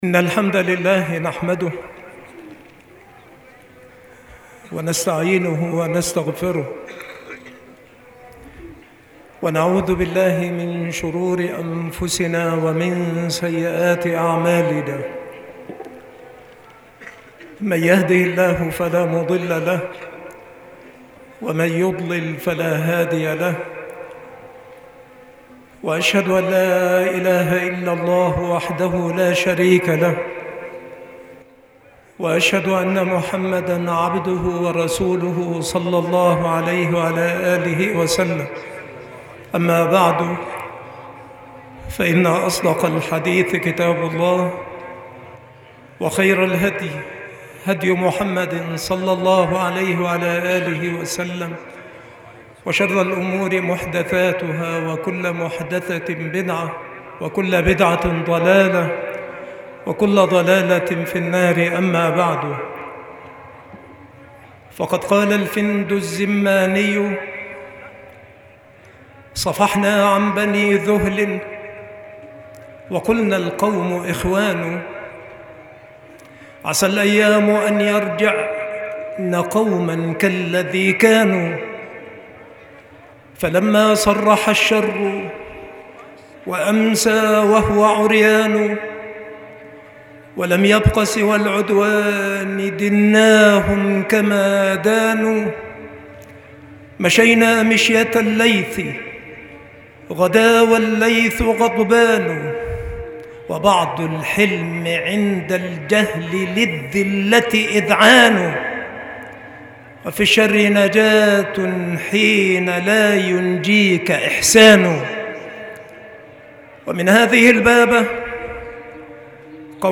خطبة الفِنْد
مكان إلقاء هذه المحاضرة بسبك الأحد - أشمون - محافظة المنوفية - مصر